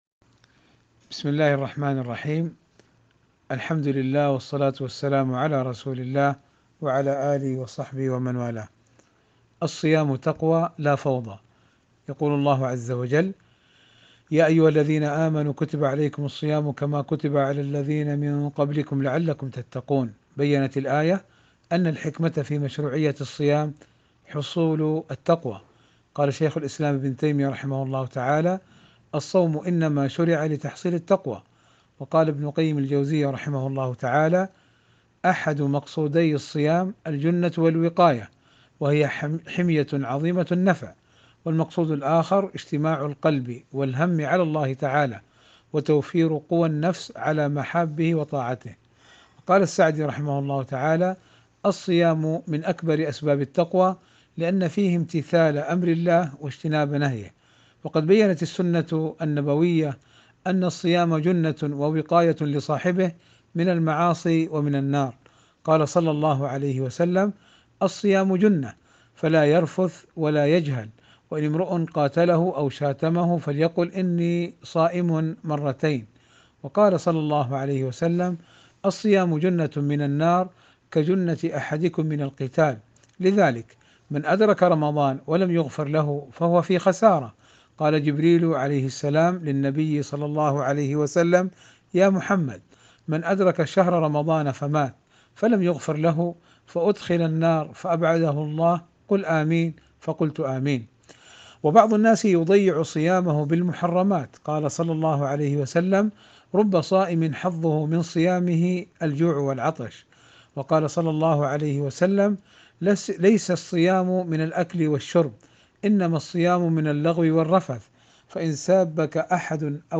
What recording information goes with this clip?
Format: MP3 Mono 44kHz 256Kbps (CBR)